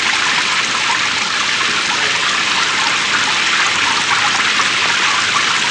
Heavy Current Sound Effect
heavy-current.mp3